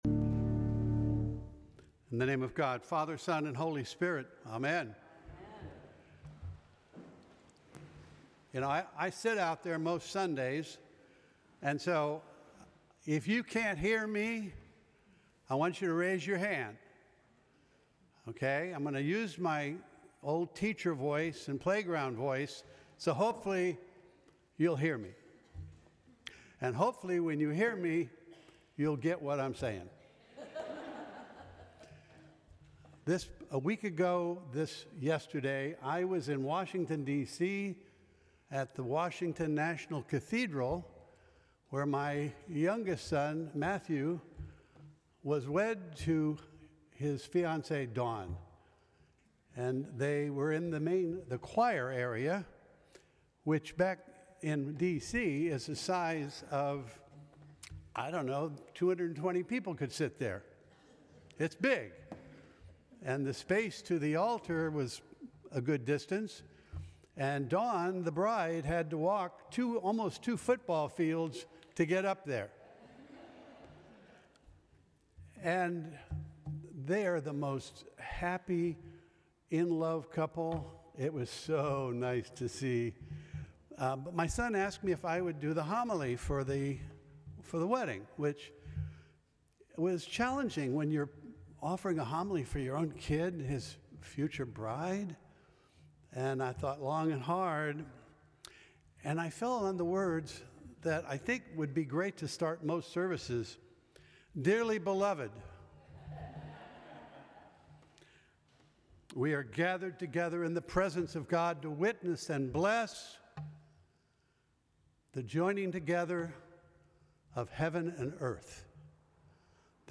Sermons from St. Cross Episcopal Church Eleventh Sunday after Pentecost Aug 26 2025 | 00:14:11 1x 00:00 / 00:14:11 Subscribe Share Apple Podcasts Spotify Overcast RSS Feed Share Link Embed